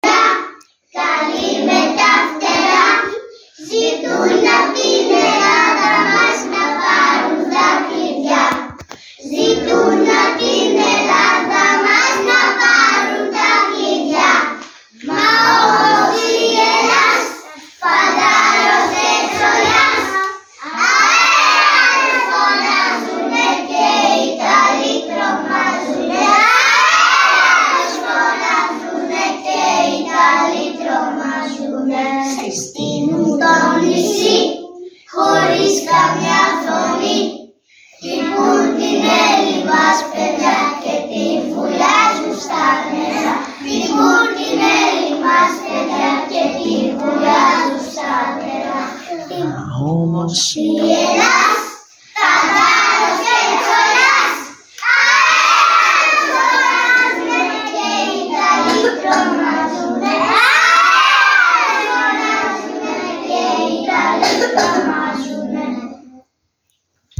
…..σας αφιερώνουμε με τις φωνούλες μας το τραγούδι ….ΣΑΝ ΣΗΜΕΡΑ ΠΑΙΔΙΑ….